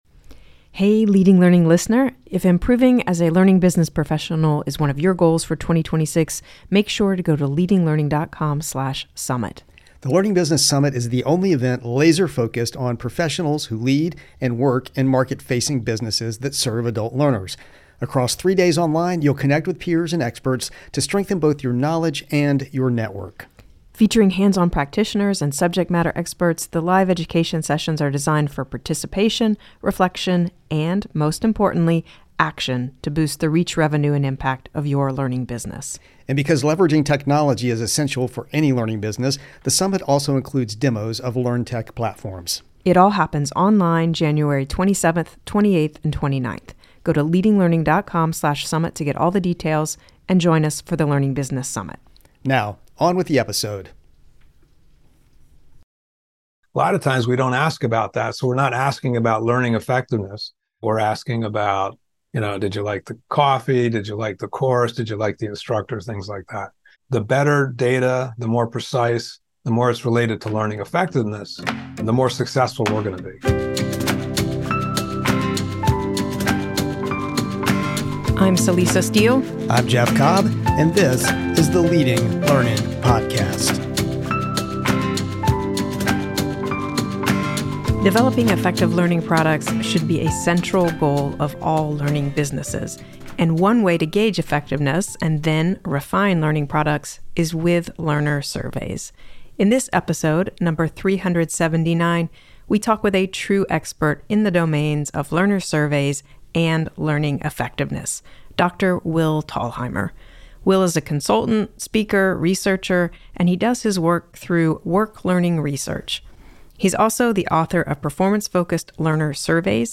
Leading Learning Podcast interviewee